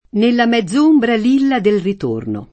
mezz’ombra [ m Hzz 1 mbra ] s. f.